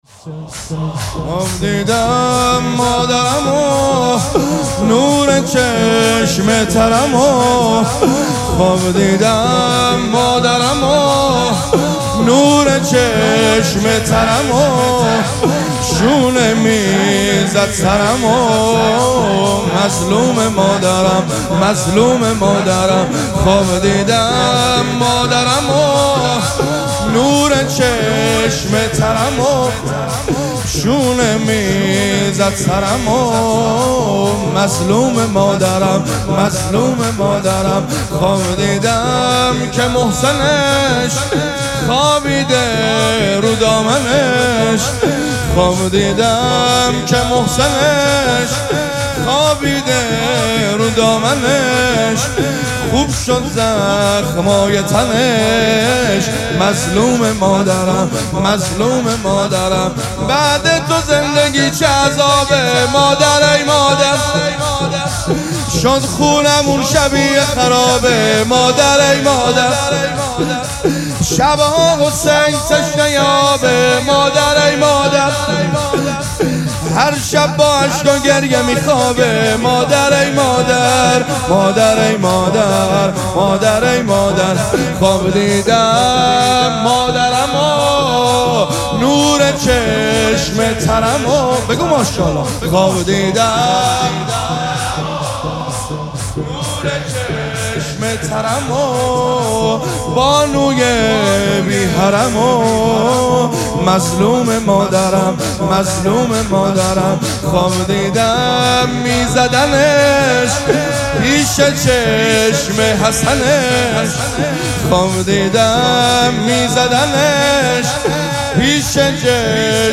شب سوم مراسم عزاداری دهه دوم فاطمیه ۱۴۴۶
شور